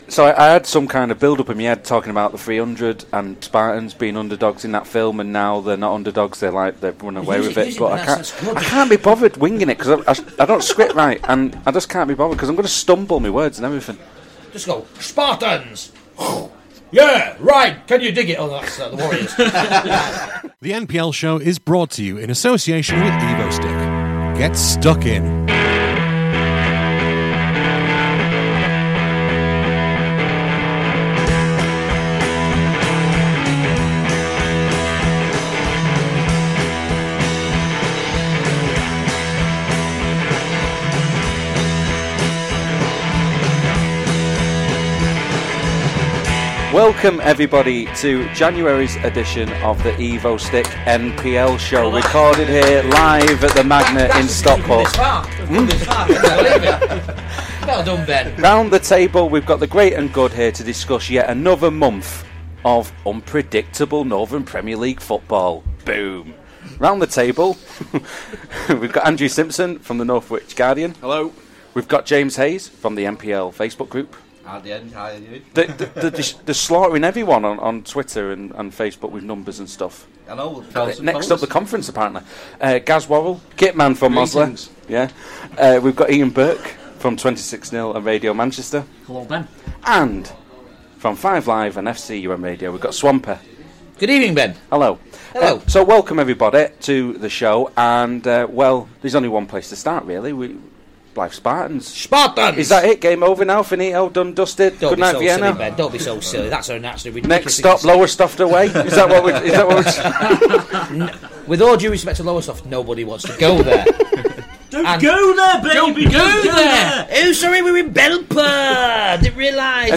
This programme was recorded at the Magnet in Stockport on Wednesday 27th January 2016.